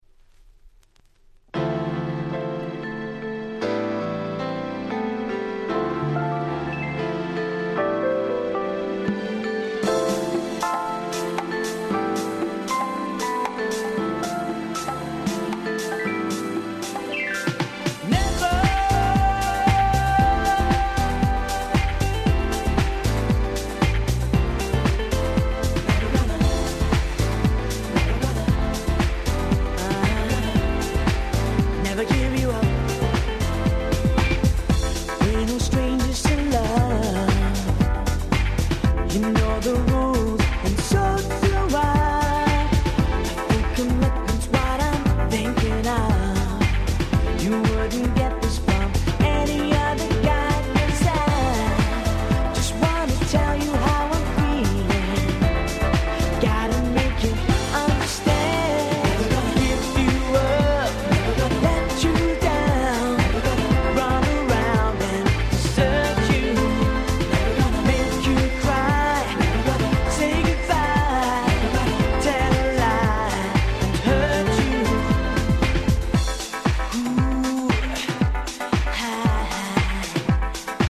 White Press Only Nice R&B Complilation !!!!!